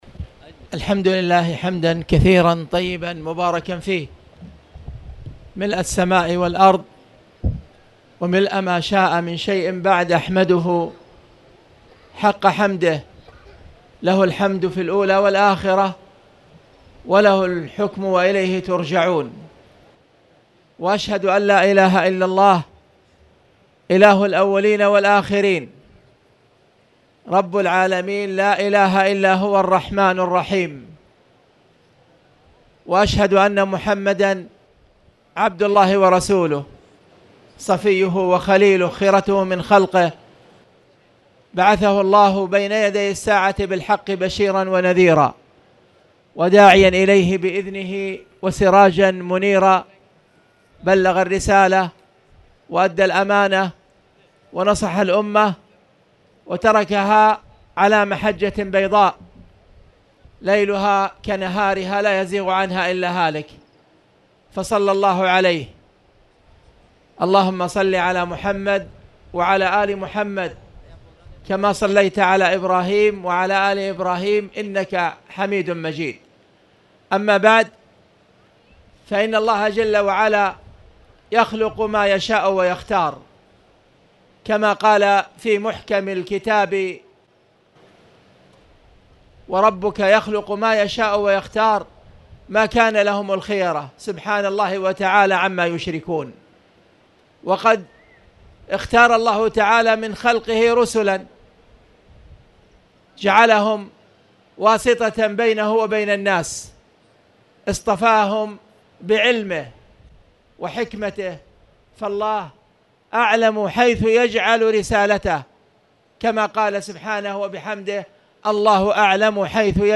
تاريخ النشر ٤ ربيع الأول ١٤٣٨ هـ المكان: المسجد الحرام الشيخ